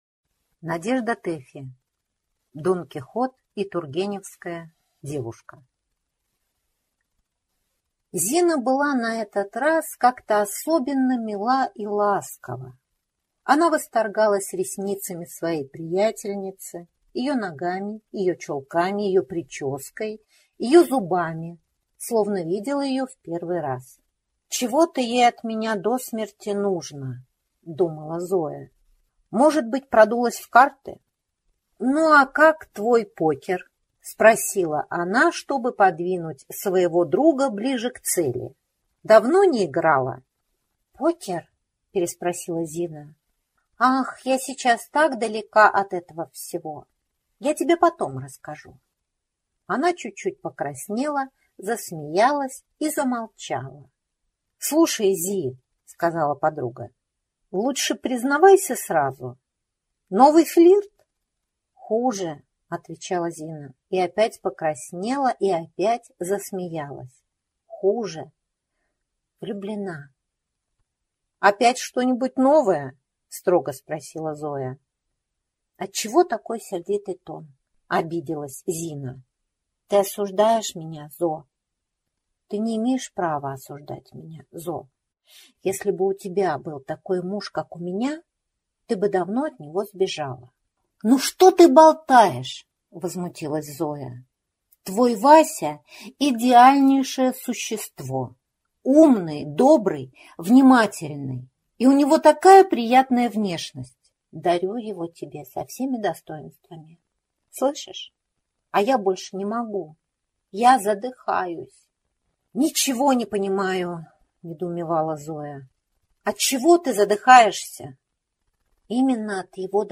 Аудиокнига Дон Кихот и тургеневская девушка | Библиотека аудиокниг